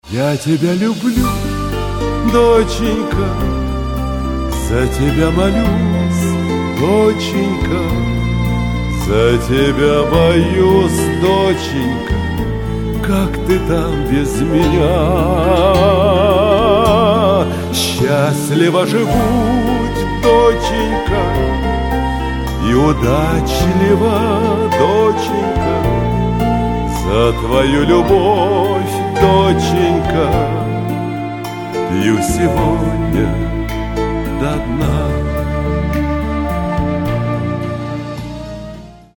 Очень душевная песня отца дочери